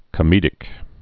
(kə-mēdĭk)